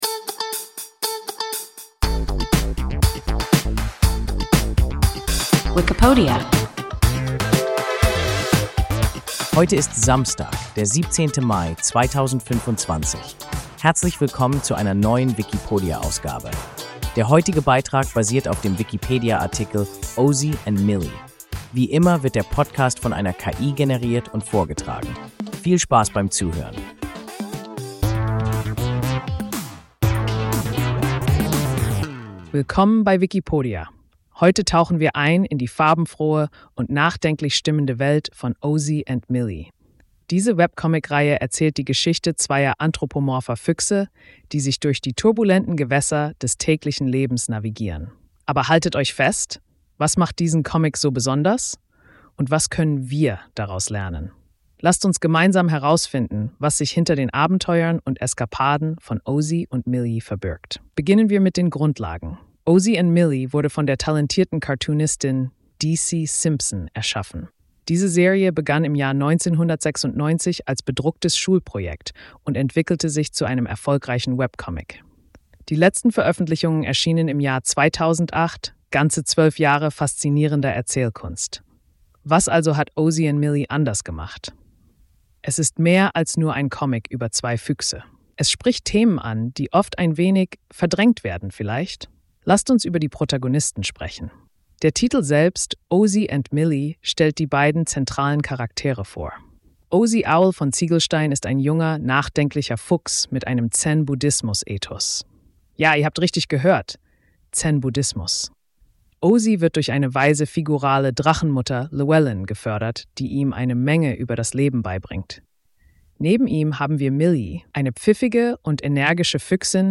Ozy and Millie – WIKIPODIA – ein KI Podcast